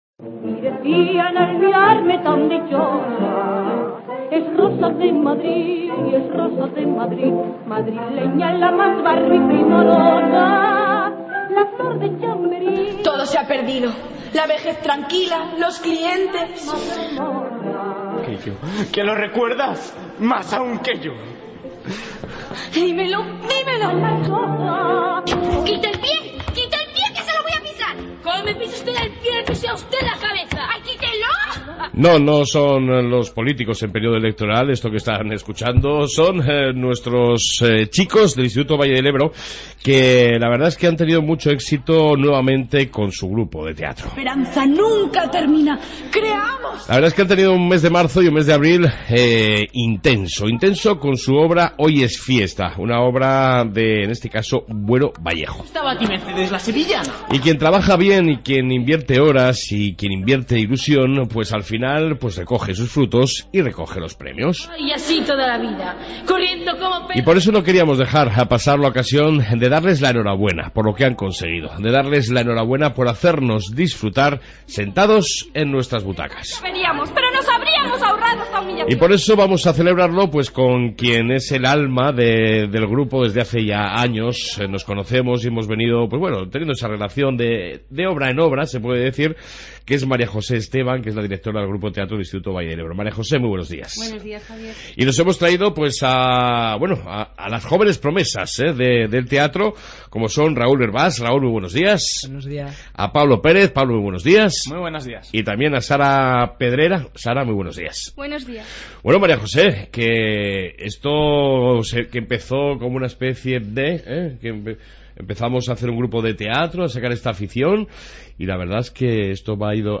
AUDIO: Entrevista Grupo de Teatro del IES Valle del Ebro